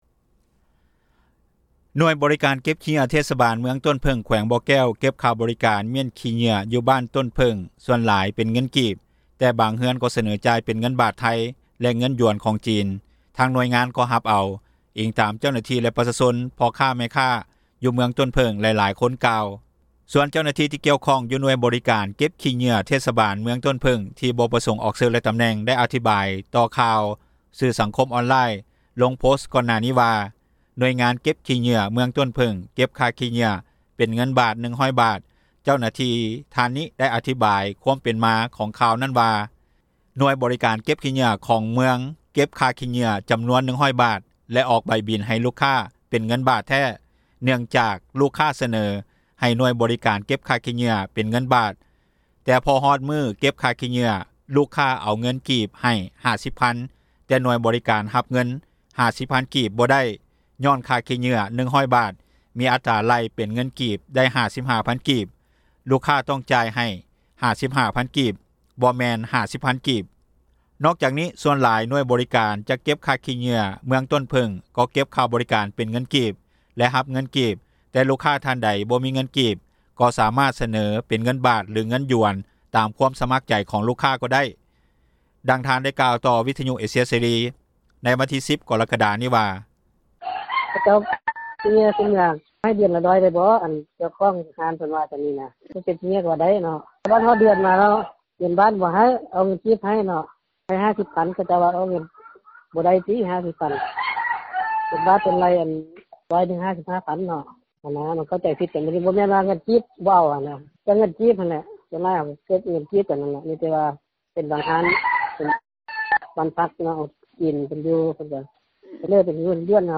ດັ່ງຊາວລາວ ຢູ່ບ້ານສີບຸນເຮືອງ ກ່າວໃນມື້ດຽວກັນວ່າ:
ດັ່ງຊາວບ້ານ ຢູ່ບ້ານດອນເງິນ ກ່າວໃນມື້ດຽວກັນວ່າ: